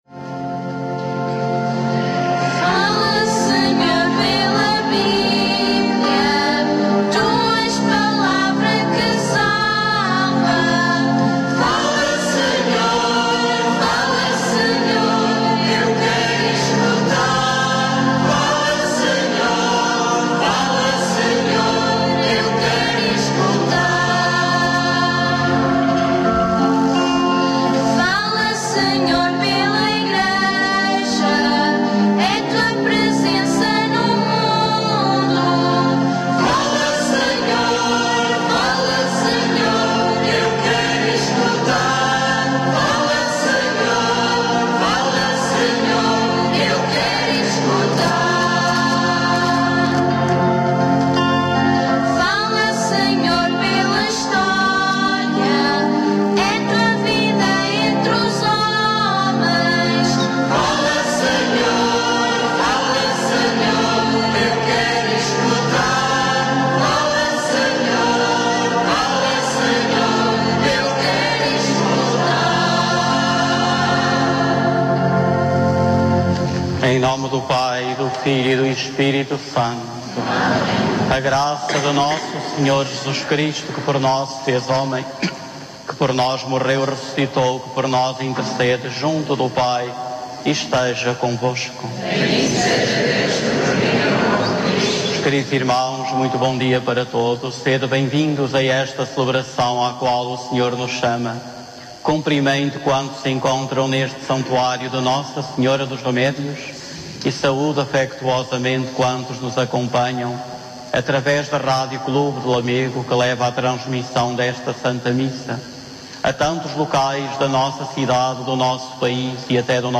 Eucaristia Dominical – 13.Julho.2014
A Rádio Clube de Lamego transmite todos os Domingos a Eucaristia, em direto, desde o Santuário de Nossa Senhora dos Remédios em Lamego a partir das 10 horas.
com a participação do Coro do Santuário de Nossa Senhora dos Remédios.